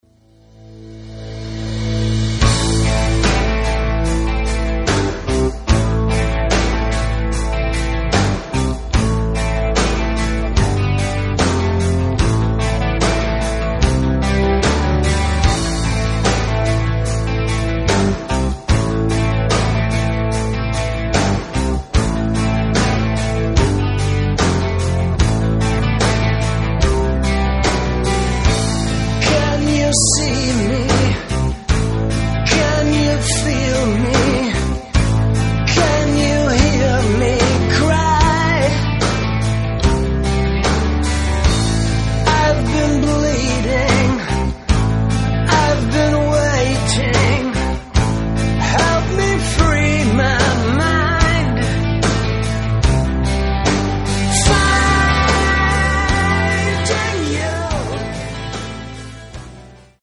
Рок
Этот альбом получился более мощным и страстным.